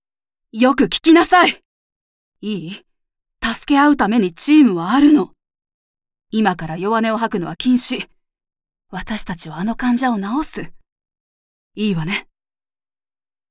ボイスサンプル
台詞